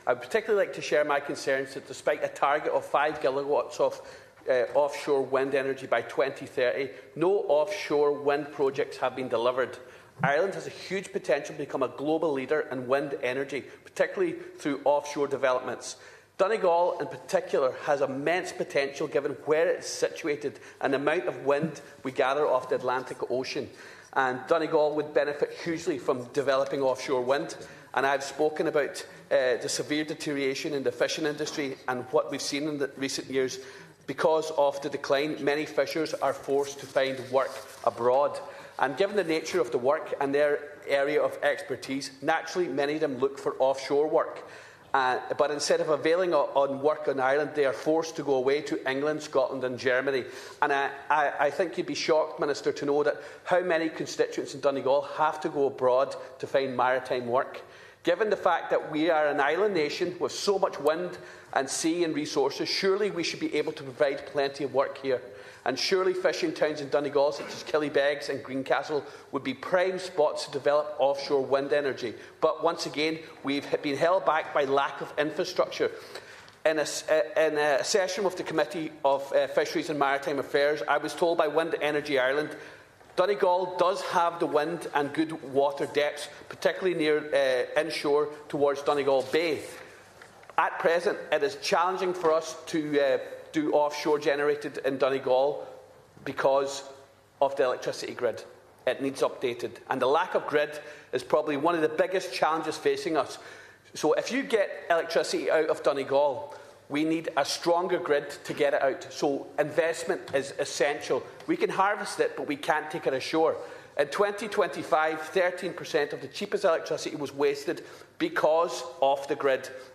Donegal Deputy Charles Ward told the Dáil that a lack of infrastructure is holding back development.
The Donegal TD says the reality in fishing communities would shock the Minister: